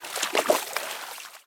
sound / walking / water-05.ogg
water-05.ogg